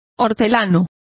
Complete with pronunciation of the translation of gardeners.